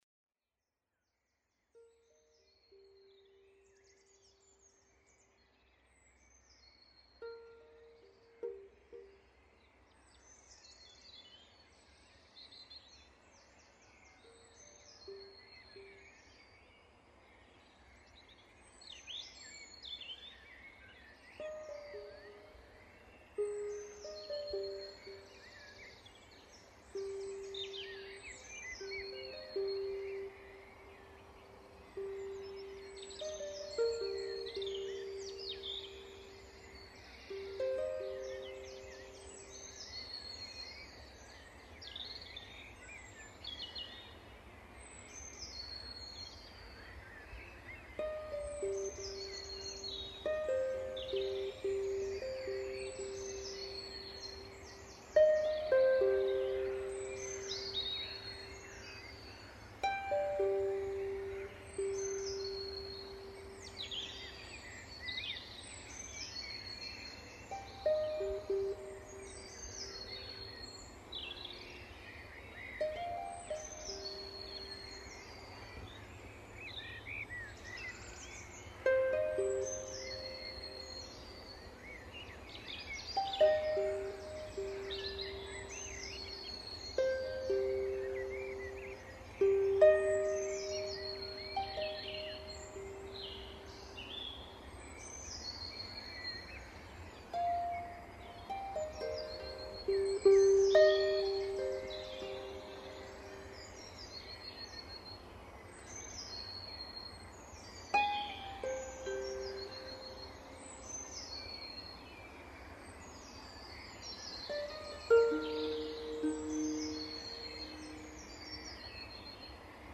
The harp sounds fantastic!
gentle, meditative music that can be uplifting and joyful
A lovely recording.